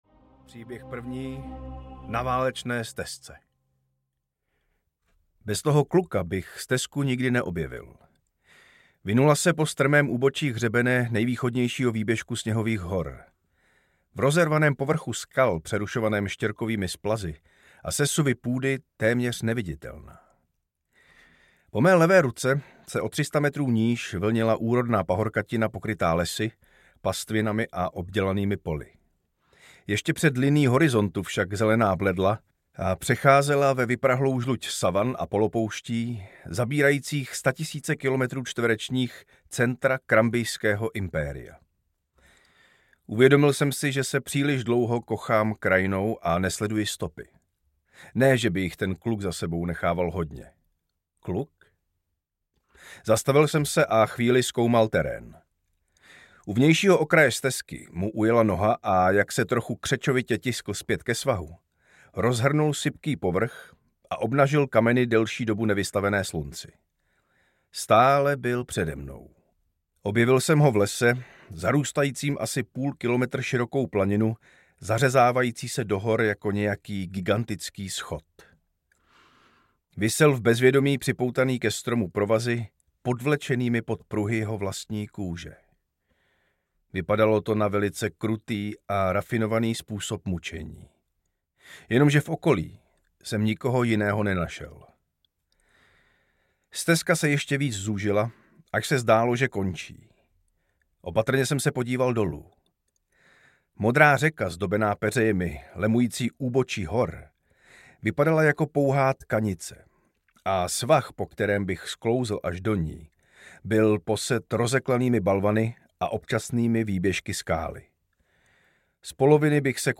Ukázka z knihy
konias-na-valecne-stezce-audiokniha